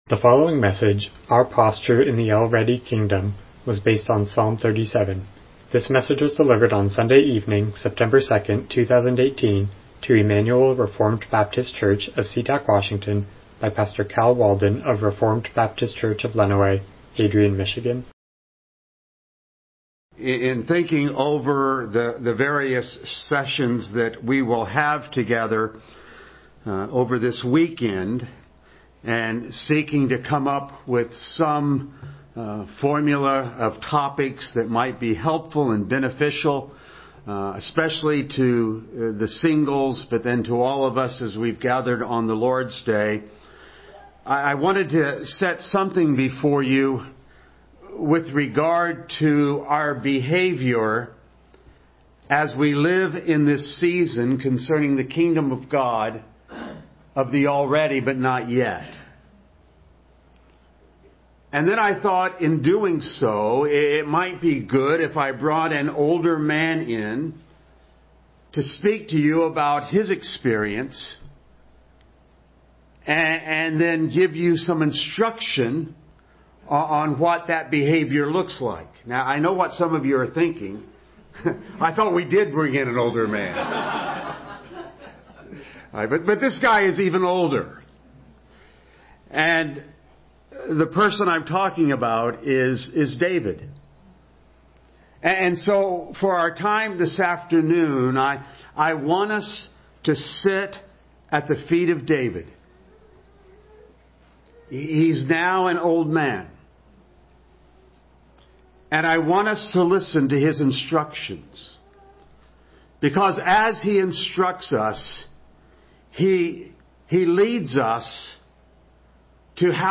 Passage: Psalm 37:1-40 Service Type: Evening Worship « Preciousness of the Kingdom Waiting on God